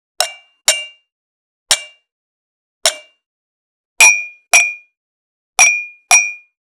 86.ワイングラス【無料効果音】
ASMRコップワイン効果音
ASMR